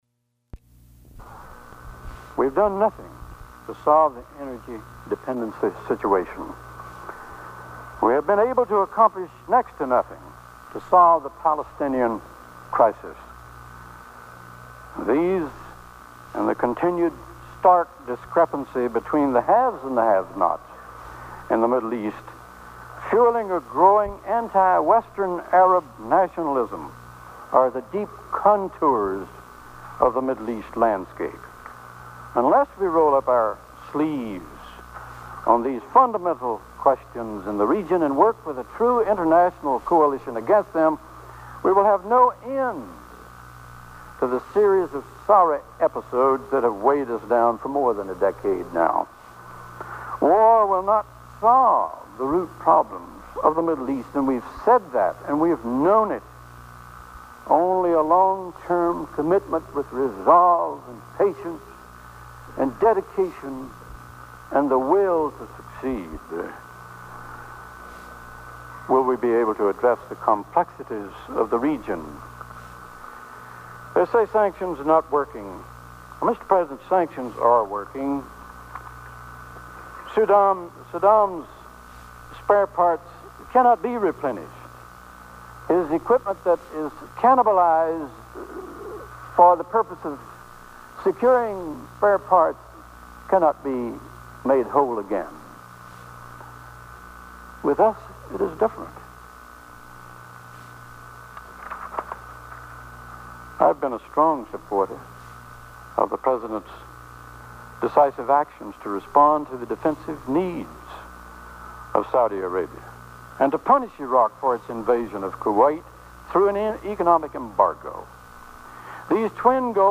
Robert Byrd cites Greco-Roman history in a speech opposing a declaration of support for war, and decries the small participation by Japan and Germany in the world effort against Saddam Hussein